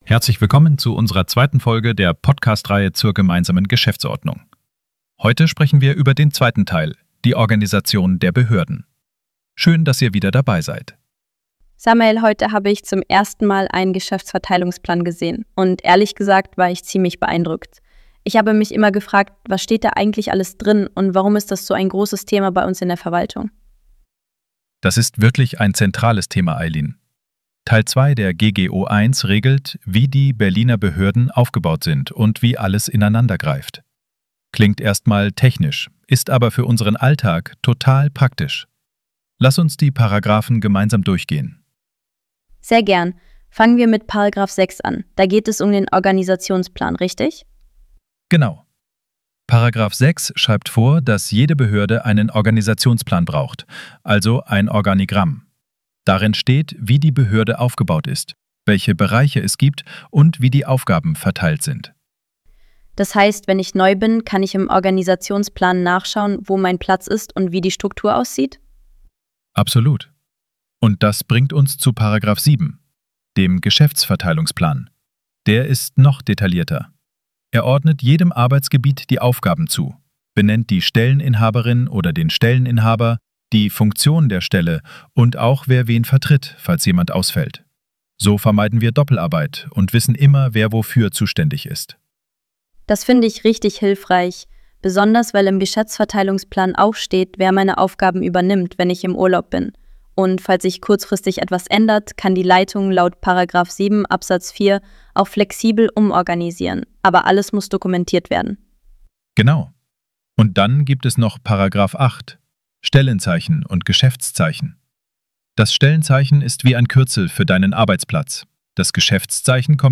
Die Erstellung dieser Podcastreihe erfolgte mit Unterstützung von Künstlicher Intelligenz.